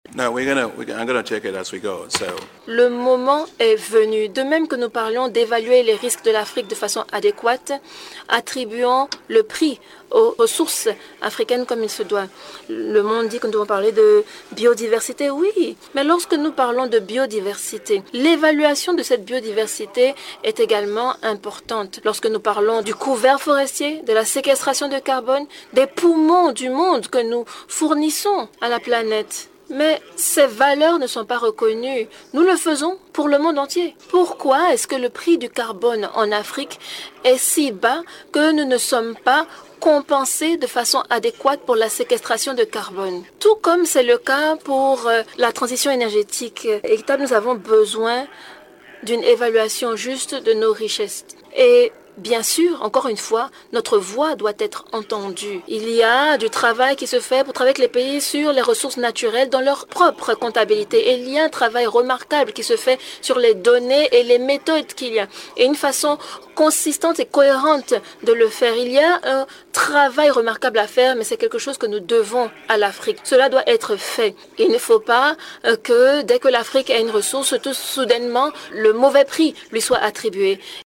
akiwemi-adesina-president-de-la-bad-a-la-cloture-de-la-58eme-assemblee-annuelle-de-la-bad.mp3